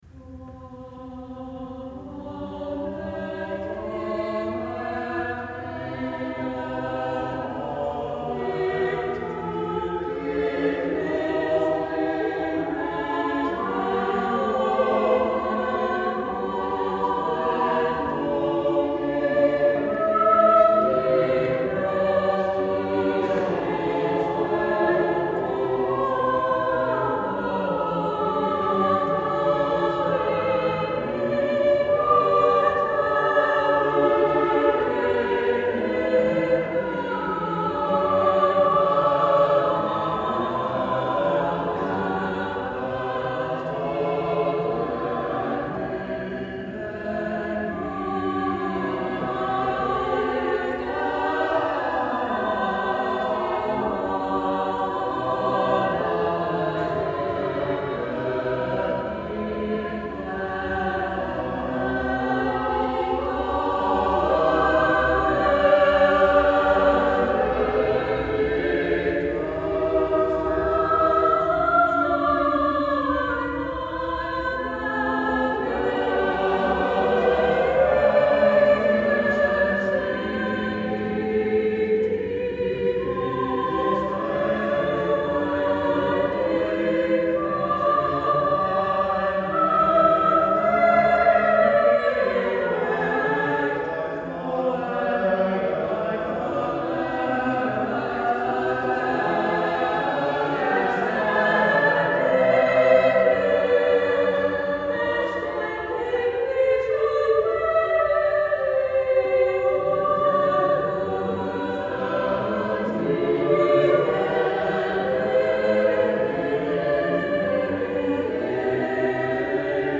six-part “German motet”
The version for 6-voices takes the form of two choirs a3 employing successive points of imitation.